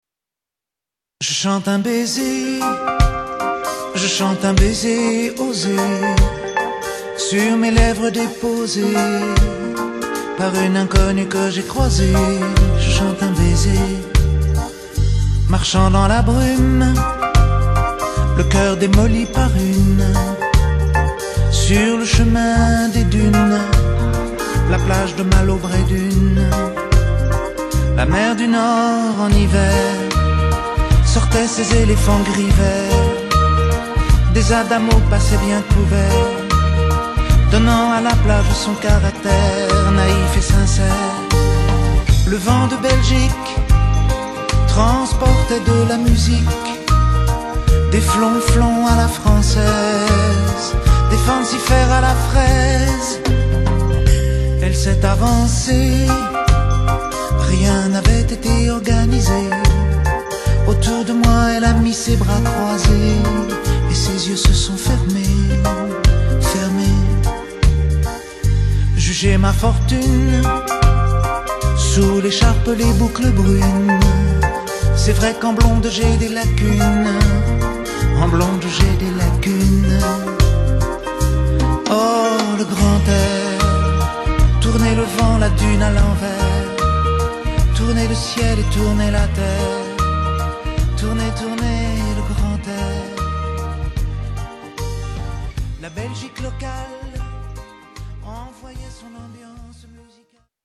tonalité MIb